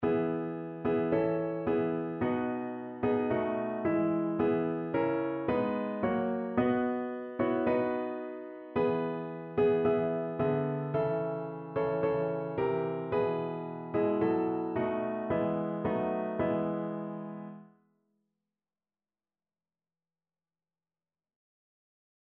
Notensatz 1 (4 Stimmen gemischt)
• SATB D-Dur [MP3] 348 KB